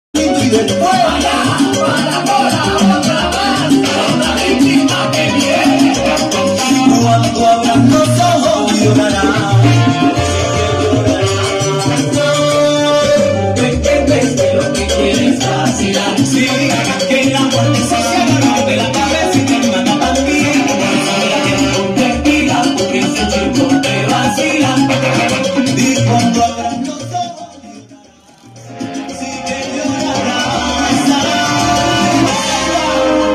Amplificador de audio Crown Itech